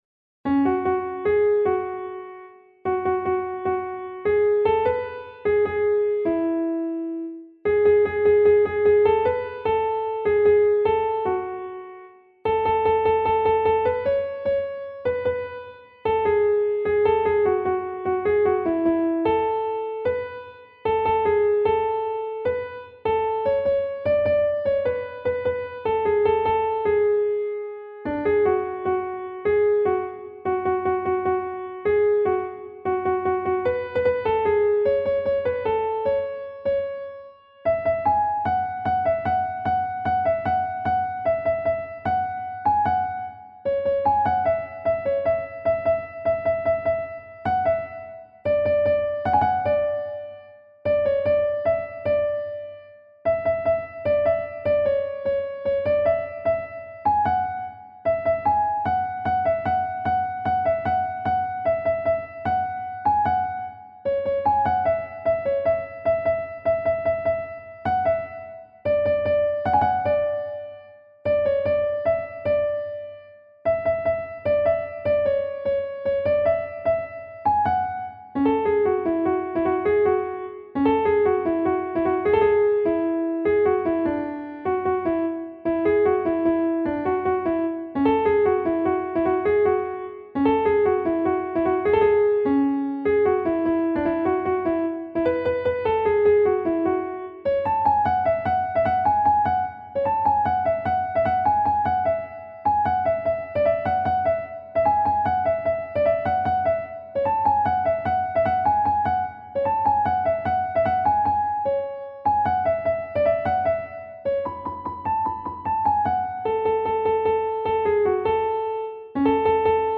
نت کیبورد